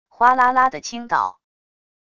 哗啦啦的倾倒wav音频